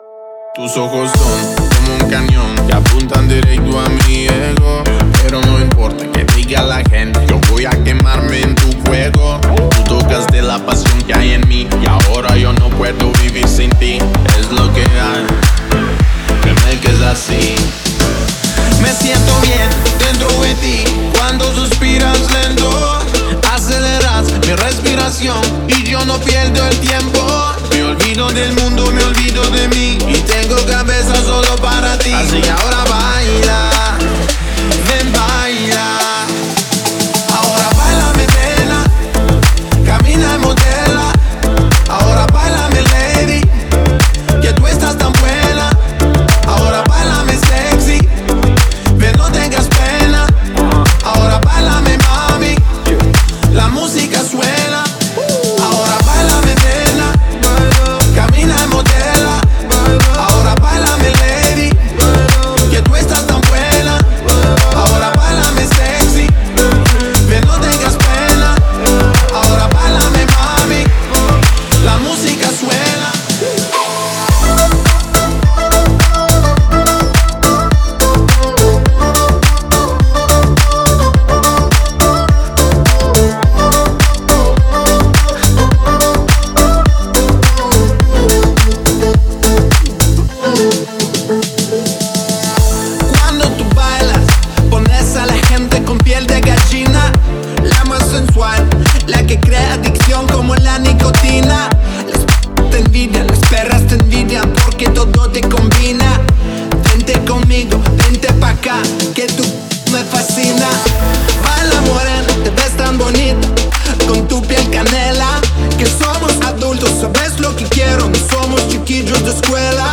это зажигательный трек в жанре поп с элементами EDM